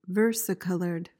PRONUNCIATION:
(VUHR-si-kuhl-uhrd)